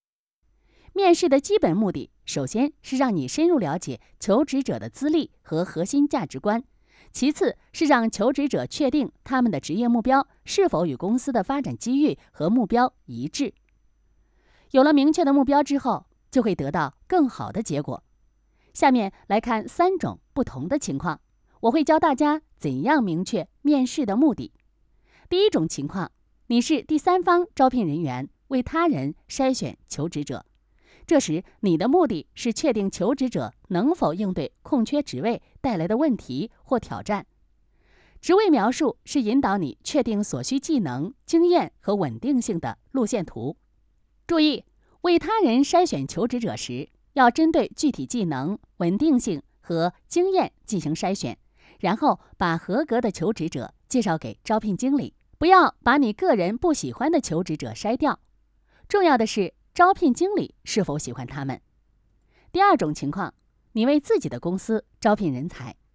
Chinese_Female_043VoiceArtist_4Hours_High_Quality_Voice_Dataset
Text-to-Speech